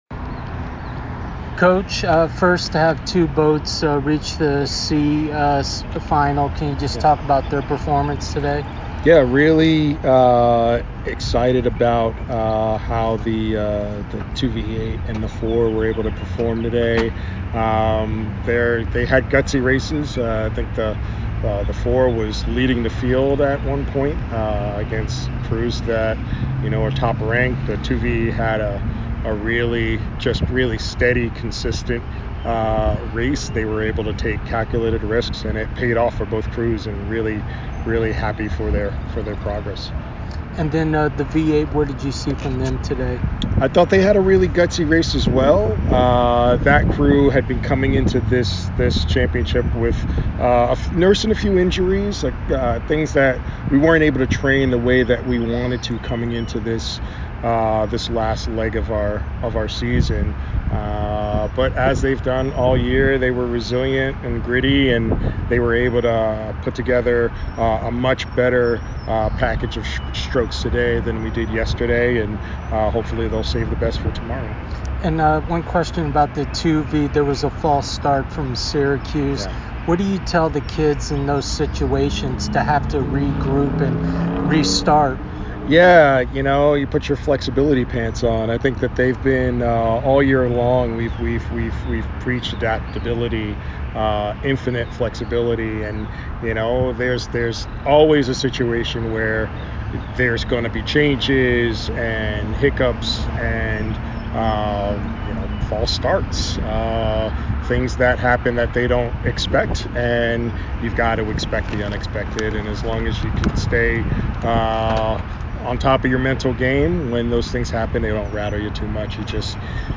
NCAA Championship Day 2 Interview